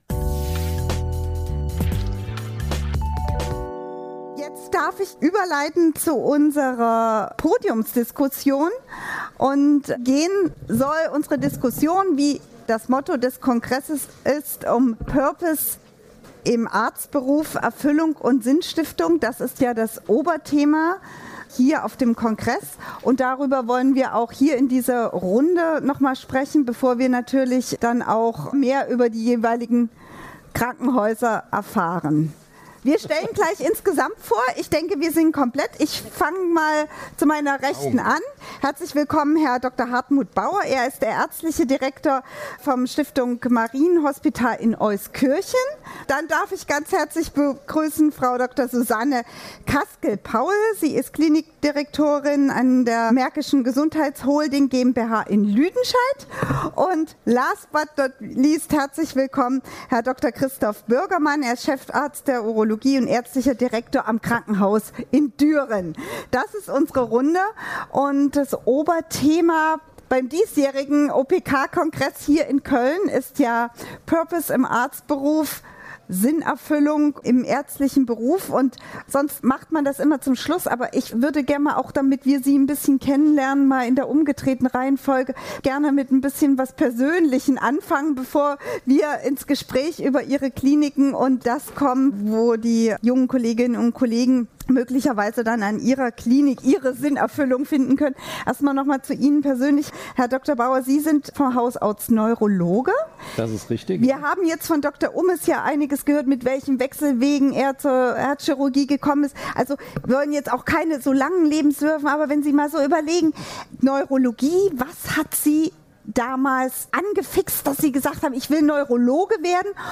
Der Arztberuf gilt bei vielen als besonders sinnstiftend. In der Podiumsdiskussion beim Operation Karriere-Event in Köln sprachen Ärztinnen und Ärzte aus verschiedenen Fachgebieten über das Thema "Purpose" im Arztberuf.